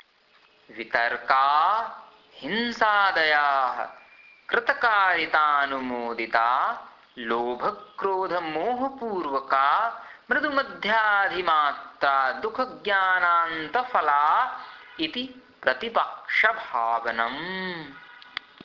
Chant  वितर्का हिंसादयः कृतकारितानुमोदिता लोभक्रोधमोहपूर्वका मृदुमध्याधिमात्रा   दुःखाज्ञानन्तफला इति प्रतिपक्षभावनम् ।।